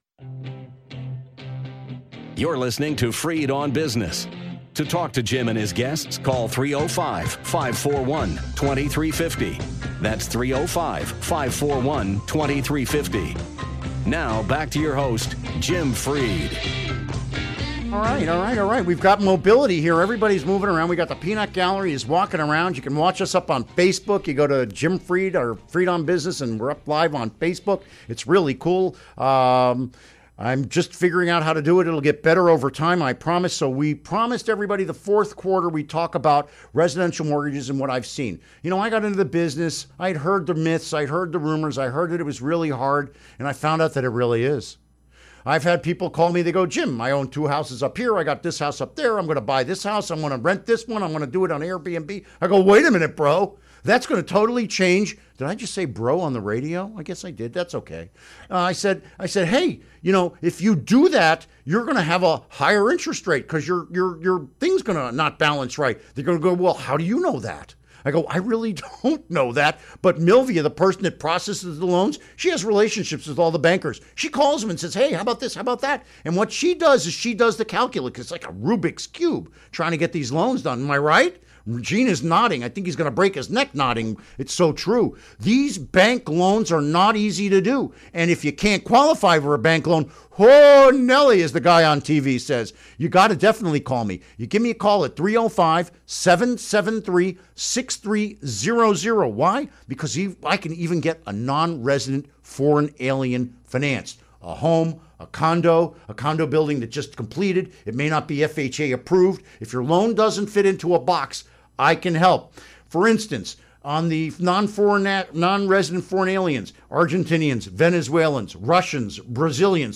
Interview Segment Episode 377: 07-14-16 Download Now!